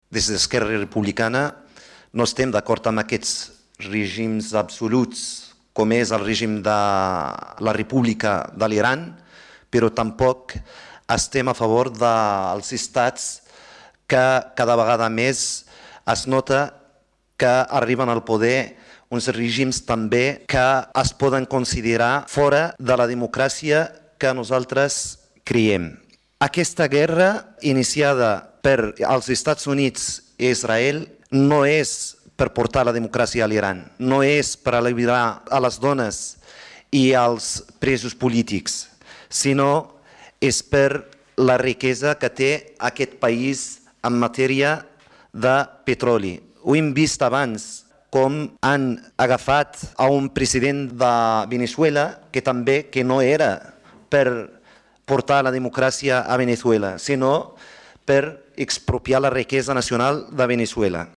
Soulimane Messaoudi, regidor d'ERC